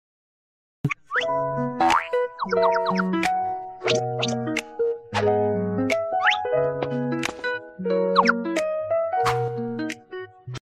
1、添加背景音乐
背景音乐，是我们之前就已经做好的，大家可以直接拿去用即可。